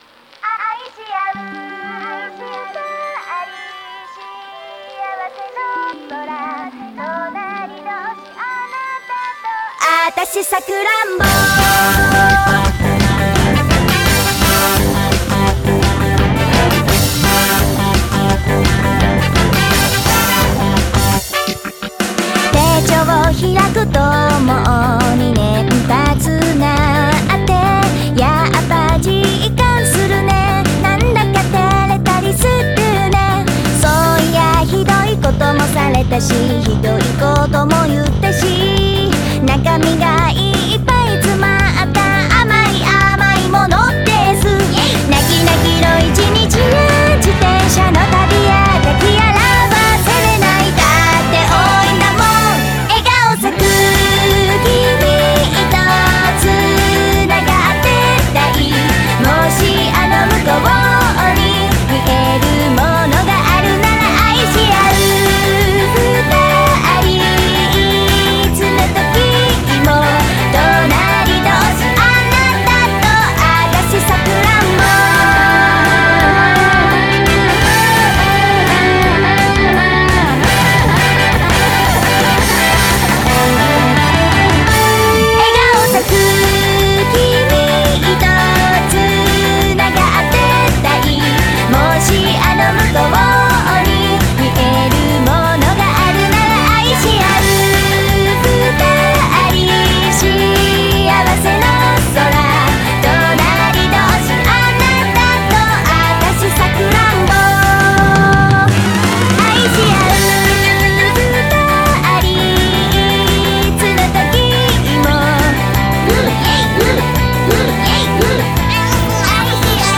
BPM42-170